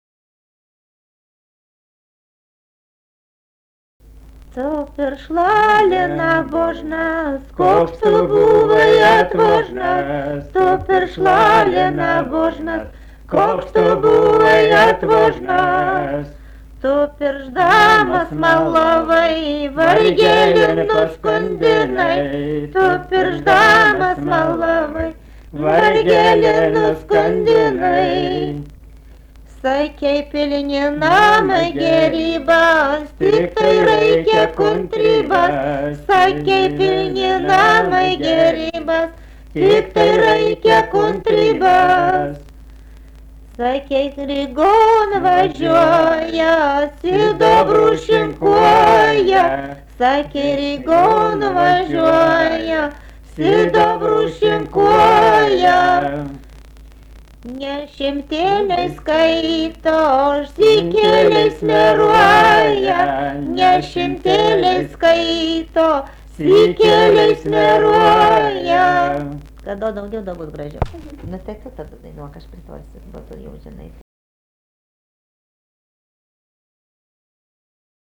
daina, vestuvių
Papiliai
vokalinis